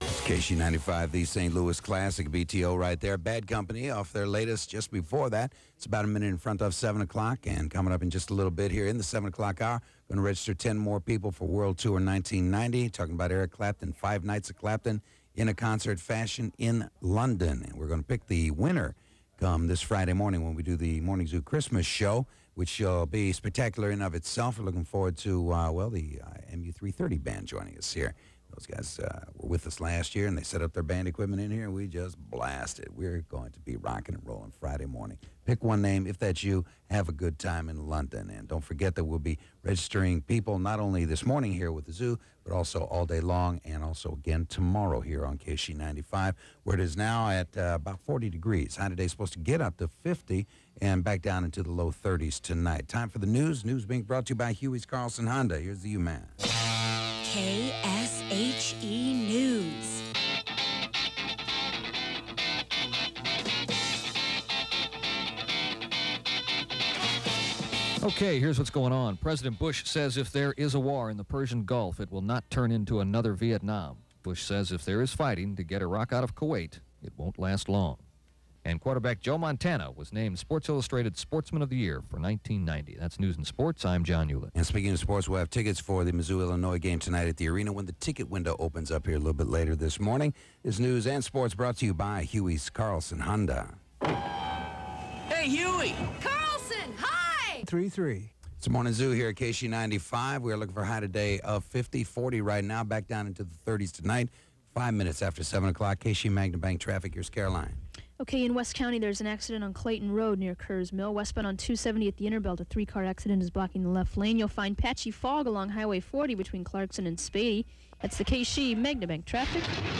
KSHE Morning Zoo Aircheck · St. Louis Media History Archive